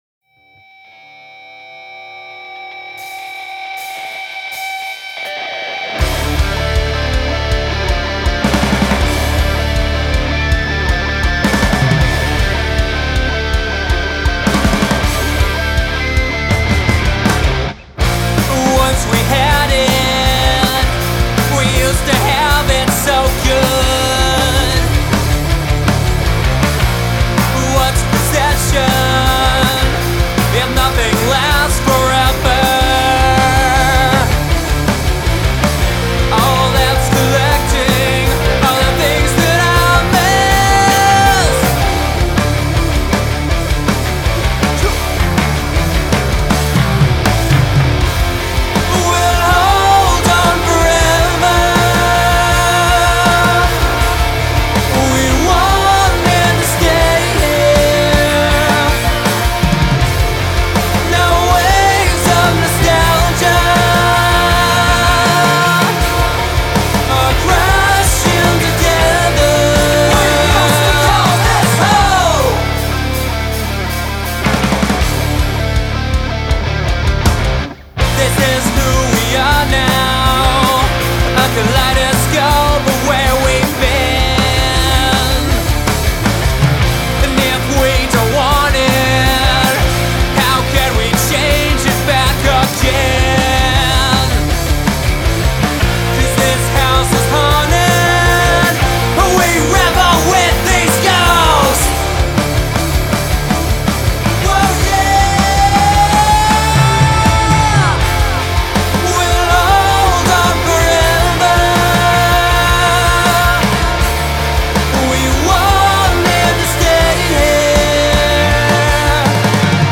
heavy rock song
heavy, nostalgic modern rock song